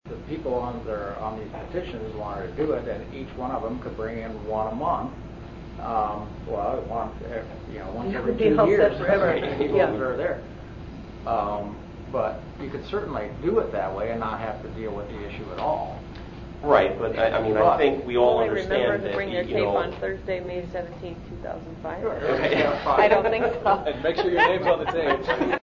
Audio clip from the meeting: